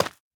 sounds / block / tuff / break4.ogg
break4.ogg